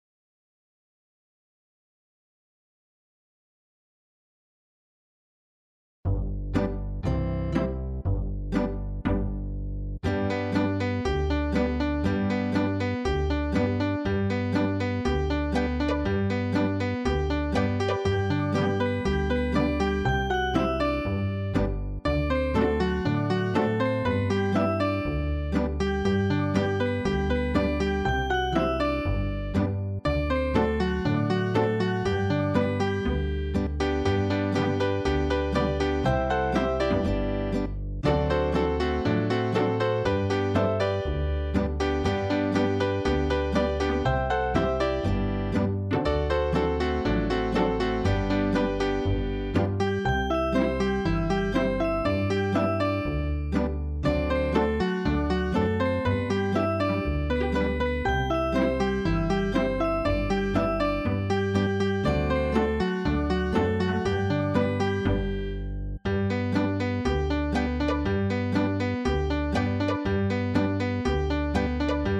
Key of G
8-beat intro.